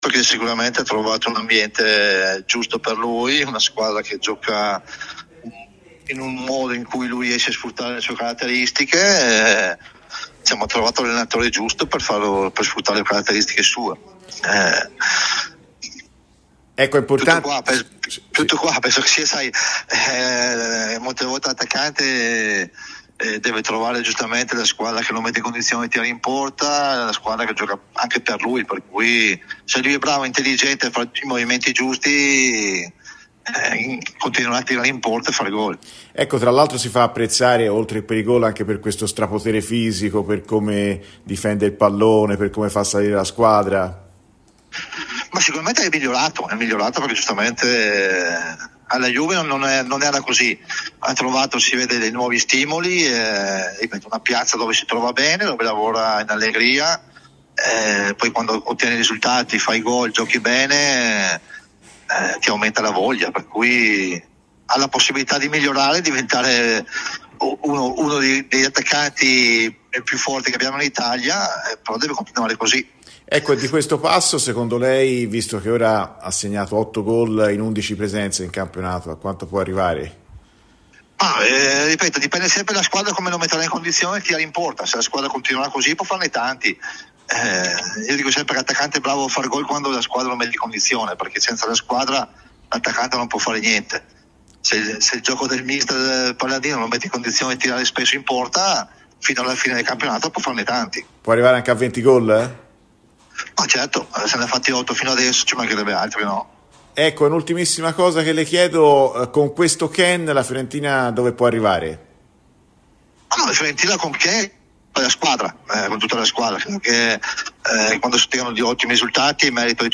L'ex attacccante Dario Hubner è intervenuto a Radio FirenzeViola durante la trasmissione "Viola Amore Mio" per parlare dell'attualità di casa viola.